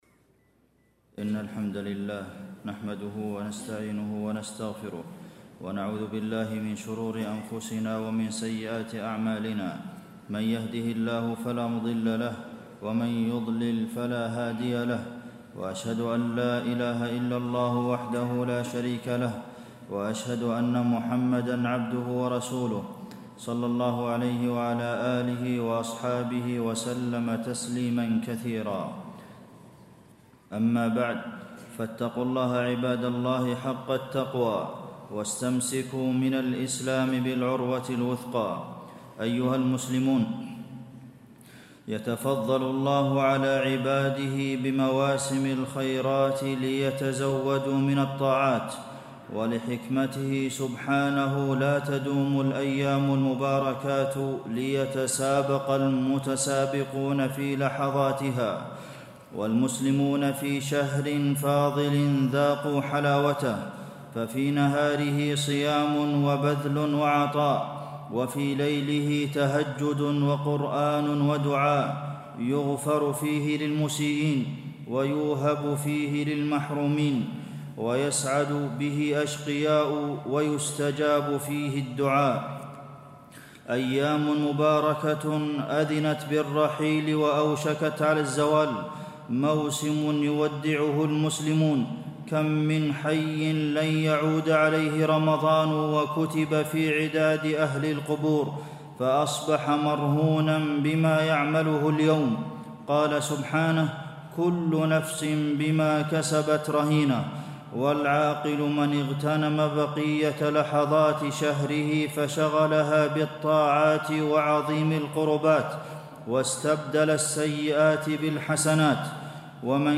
تاريخ النشر ٢٥ رمضان ١٤٣٤ هـ المكان: المسجد النبوي الشيخ: فضيلة الشيخ د. عبدالمحسن بن محمد القاسم فضيلة الشيخ د. عبدالمحسن بن محمد القاسم قبل رحيل رمضان